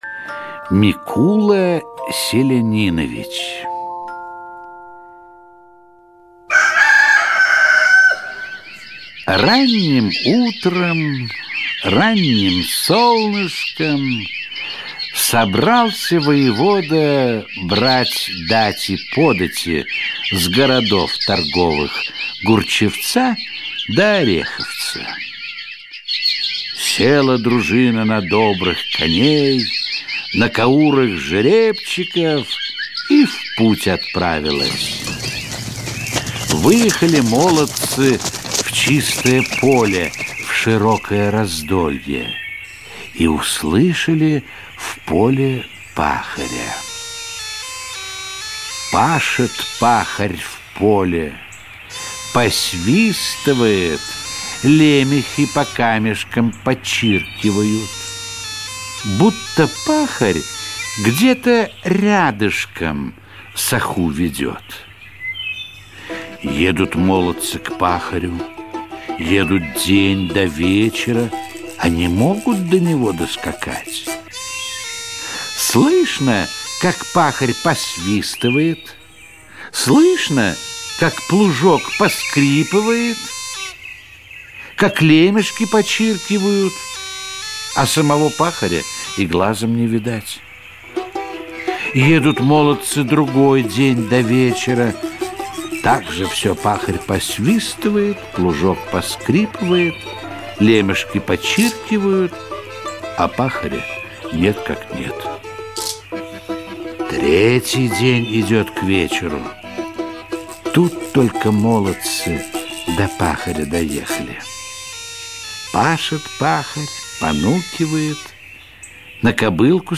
Микула Селянинович - русская народная аудиосказка - слушать онлайн